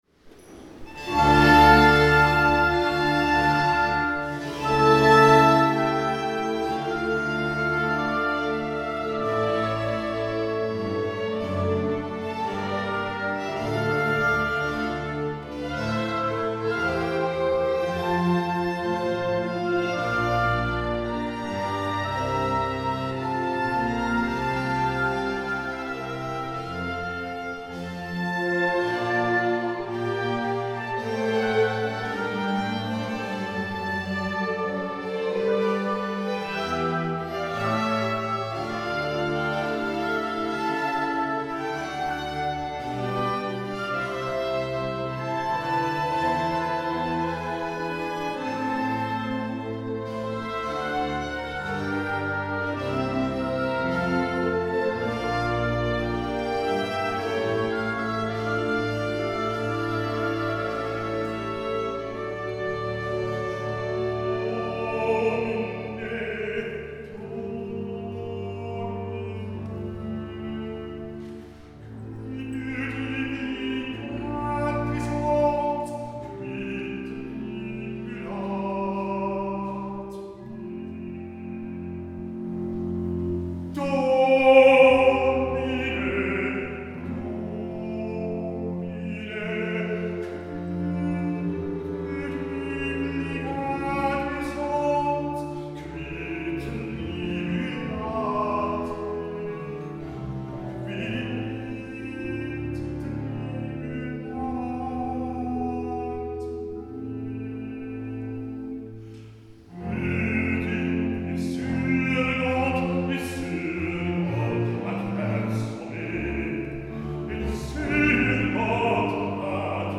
Succ�s retentissant pour le Bach Ensemble Amsterdam � l��glise du village